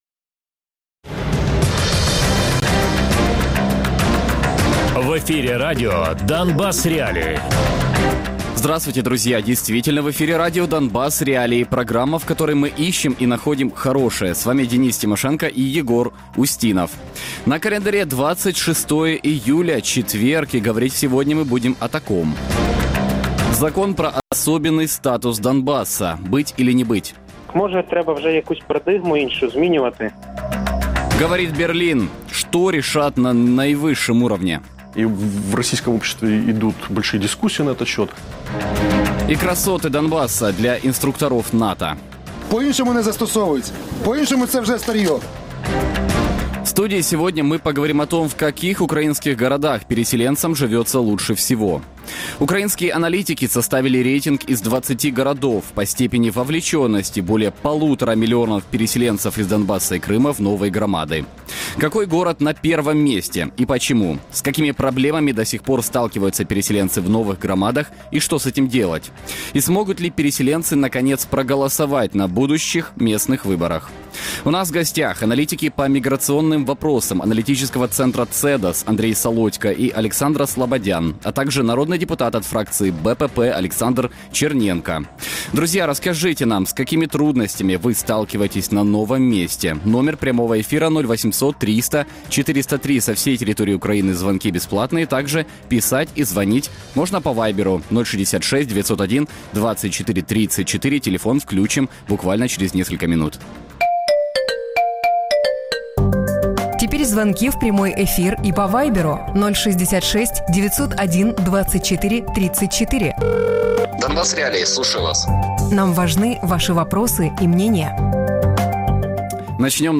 аналітики з міграційних питань Аналітичного центру CEDOS Радіопрограма «Донбас.Реалії» - у будні з 17:00 до 18:00. Без агресії і перебільшення. 60 хвилин про найважливіше для Донецької і Луганської областей.